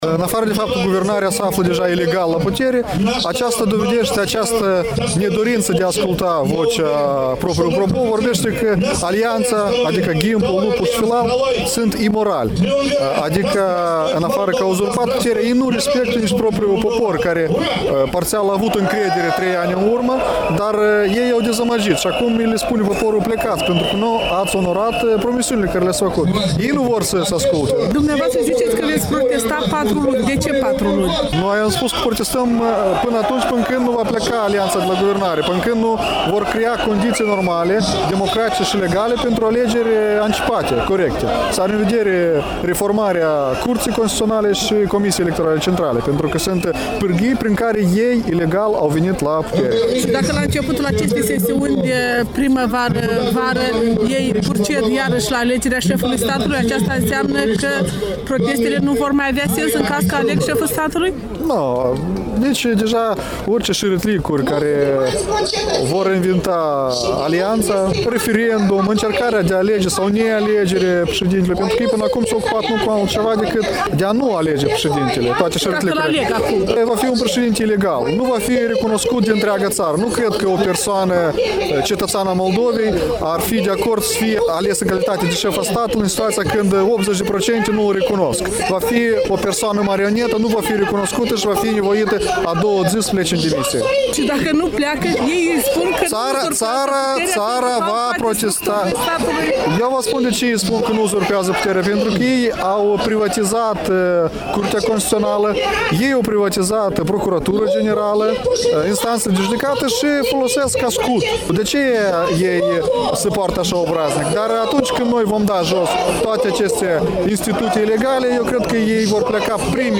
Interviu cu deputatul comunist Artur Reşetnicov.